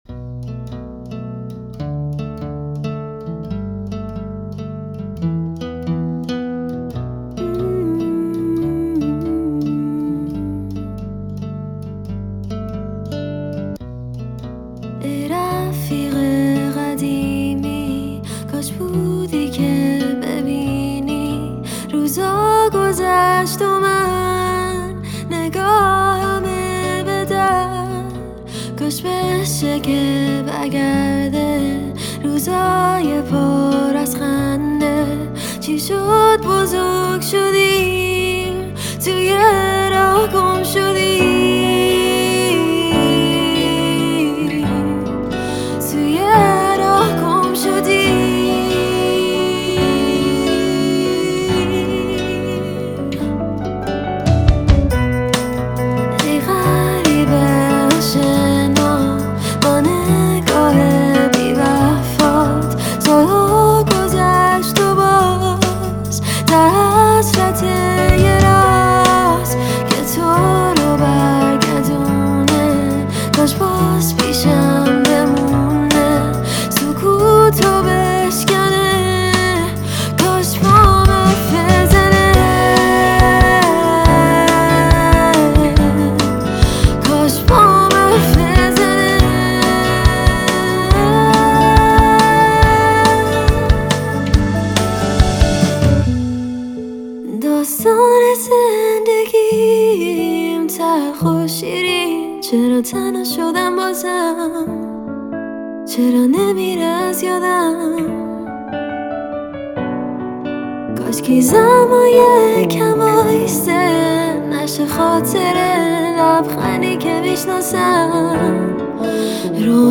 رپ
آهنگ با صدای زن